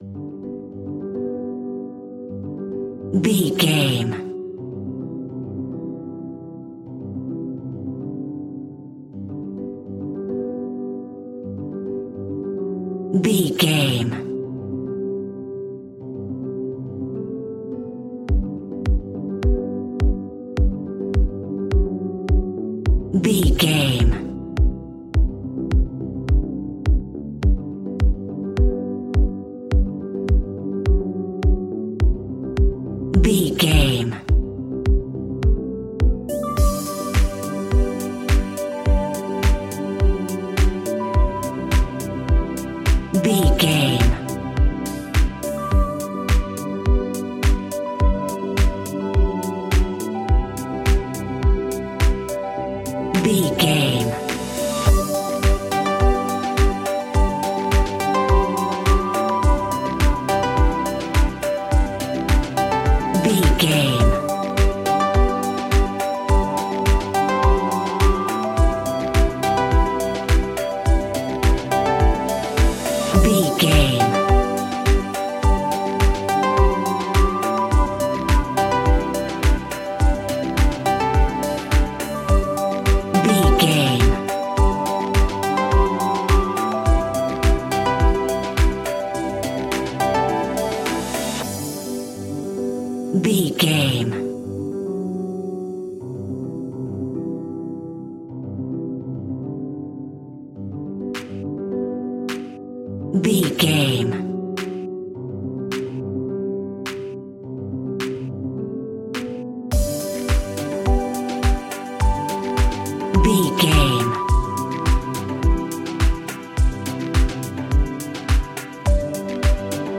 Ionian/Major
groovy
uplifting
driving
energetic
cheerful/happy
repetitive
synthesiser
drums
electric piano
strings
harp
electronic
instrumentals
synth bass